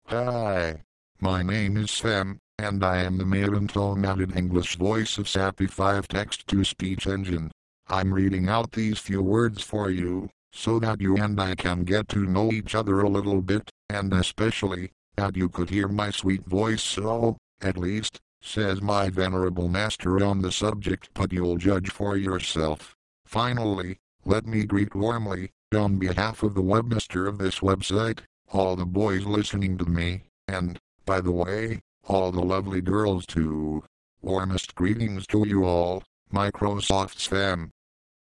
Texte de démonstration lu par Sam, voix masculine anglaise de Microsoft Text-To-Speech Sapi 5
Écouter la démonstration de Sam, voix masculine anglaise de Microsoft Text-To-Speech Sapi 5